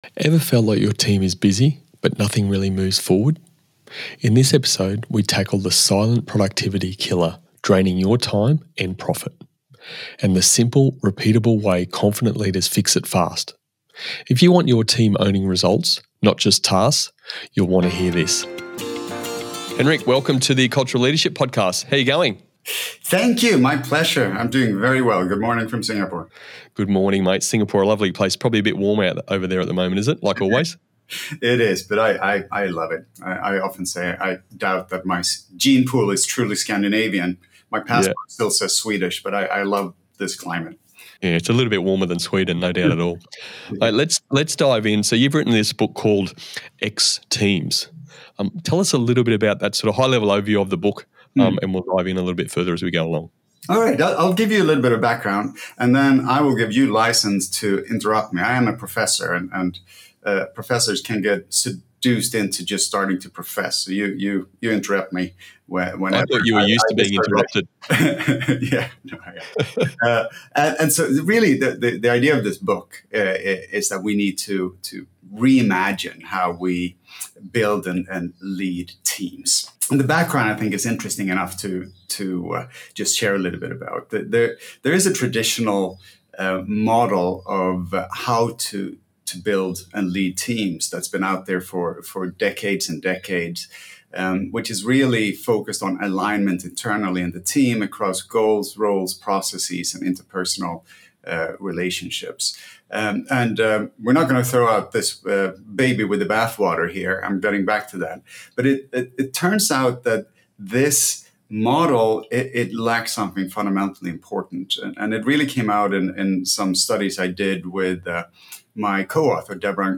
From overcoming discomfort to asking tough questions, this conversation offers practical strategies for leaders to navigate complexity and